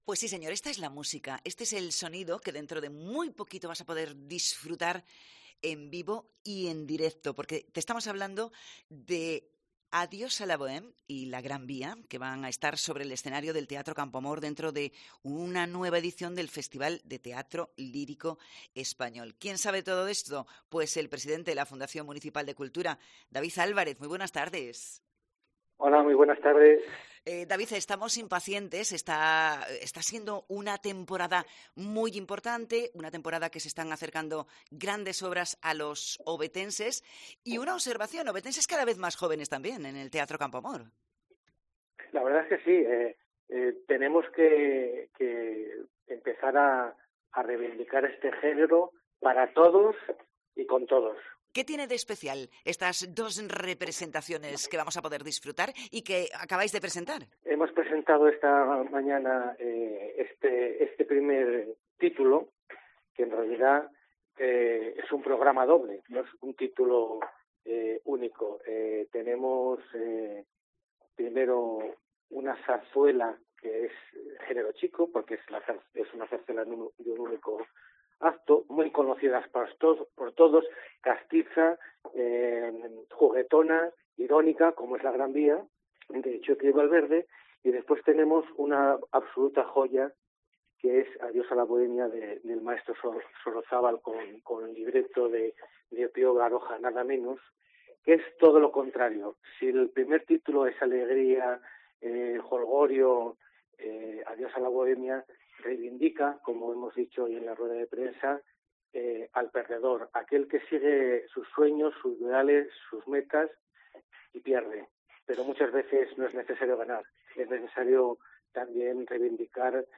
Entrevista David Álvarez, presidente de la Fundación Municipal de Cultura